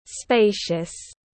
Rộng rãi tiếng anh gọi là spacious, phiên âm tiếng anh đọc là /ˈspeɪ.ʃəs/ .
Spacious /ˈspeɪ.ʃəs/